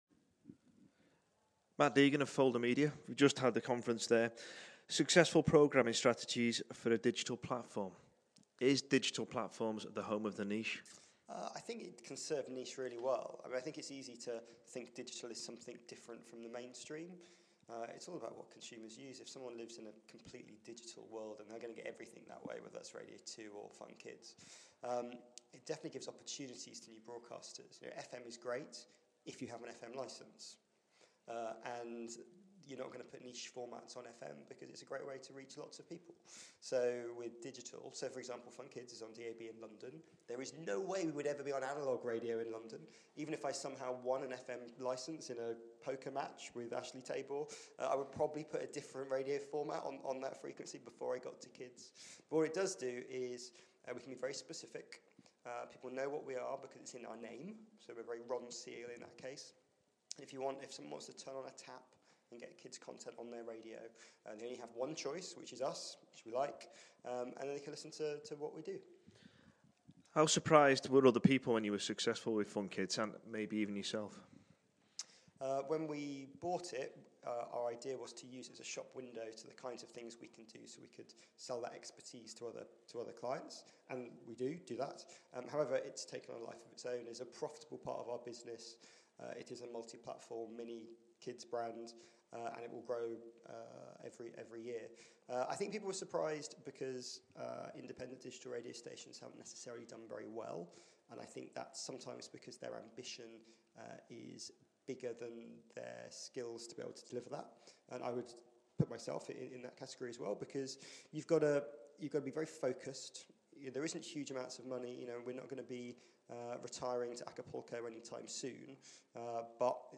RadioToday Live Interviews